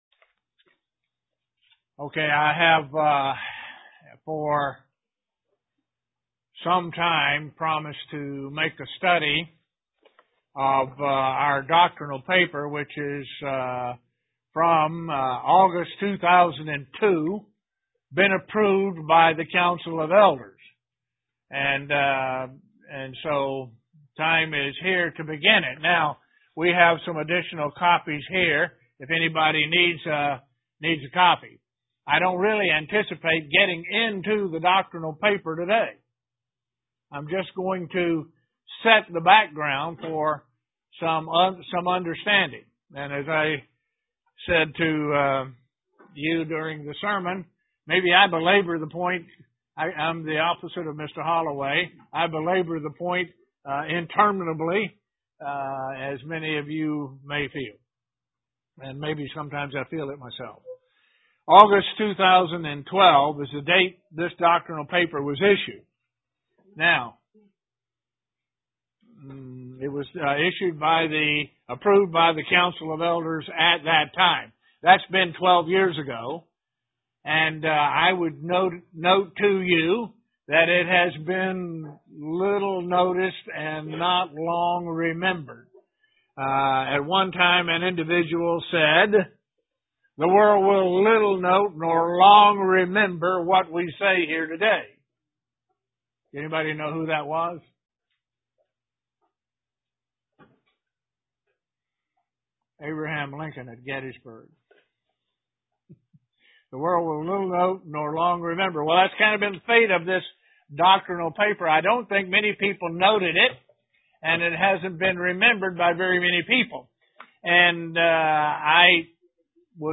Bible Study Part 1 of a Bible study about the term Last Great Day
Given in Elmira, NY